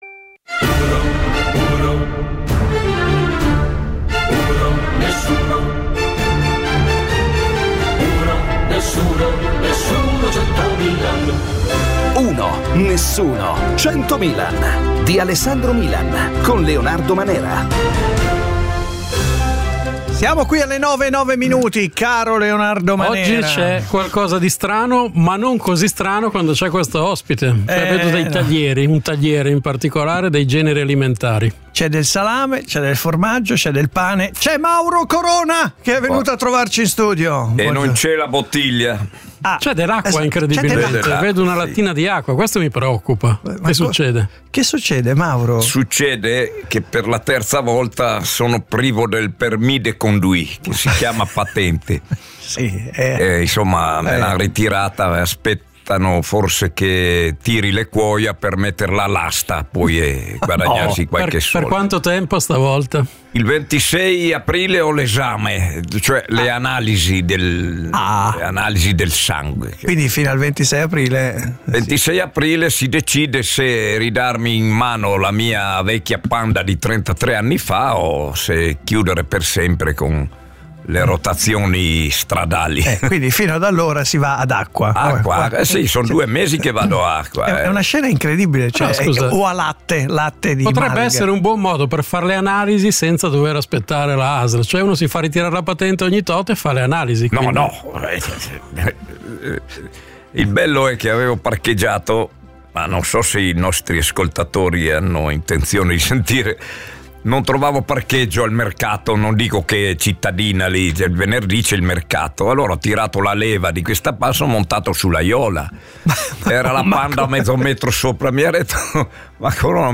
Ne parliamo con Lucetta Scaraffia, storica e giornalista.